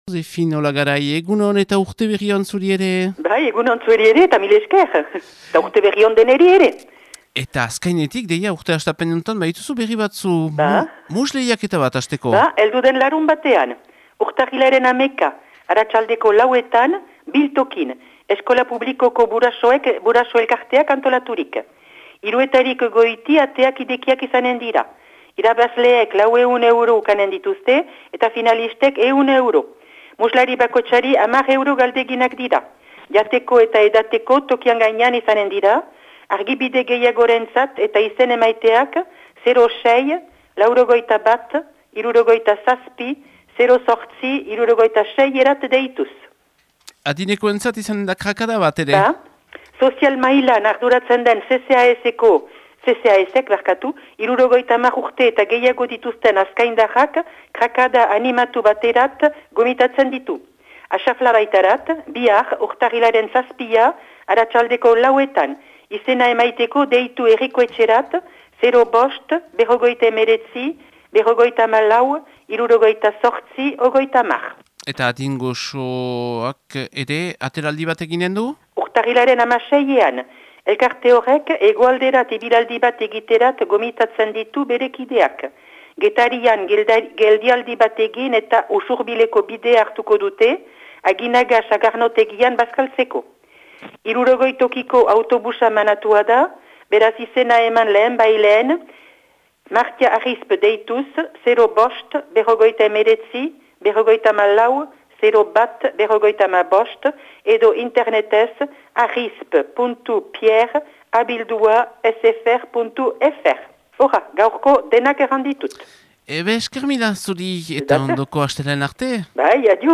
laguntzailearen berriak.